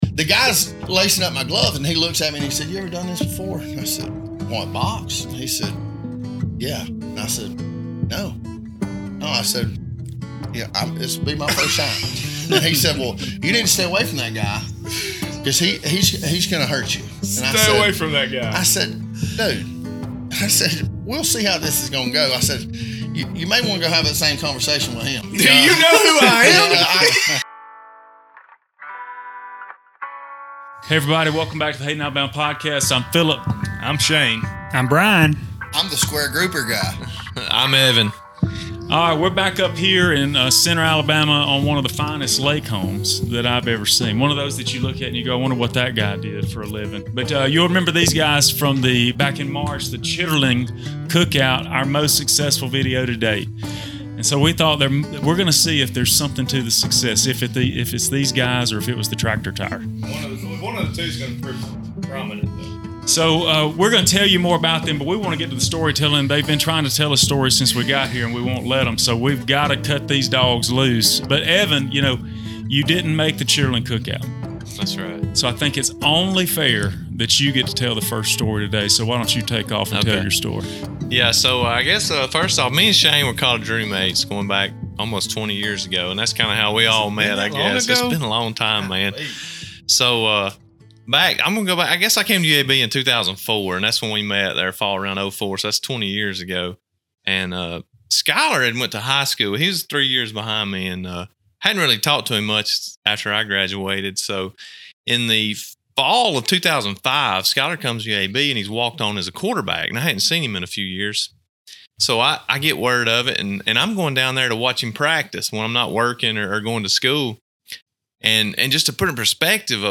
Join us as five southern boys gather to recount their wild and hilarious tales from the past at a local lake house in Centre, Alabama. From epic brawls to unexpected encounters with mischievous donkeys, these stories will have you laughing out loud and on the edge of your seat.